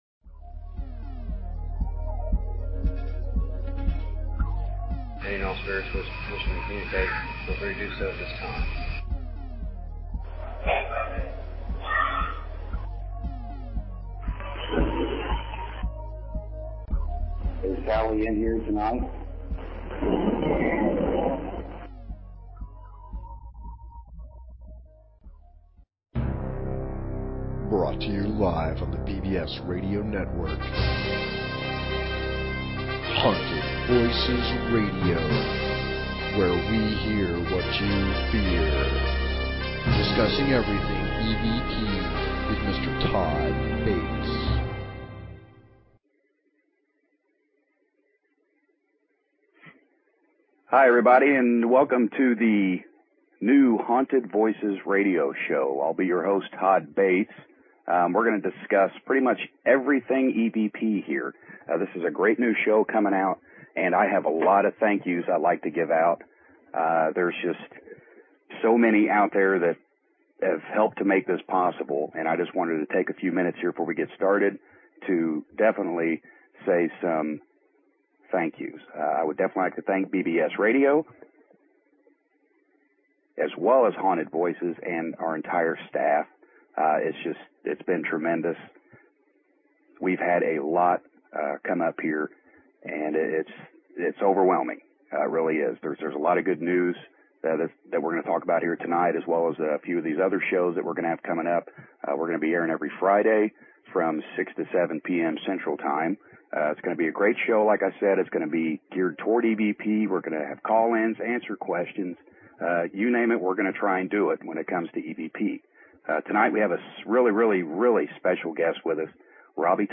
Talk Show Episode, Audio Podcast, Haunted_Voices and Courtesy of BBS Radio on , show guests , about , categorized as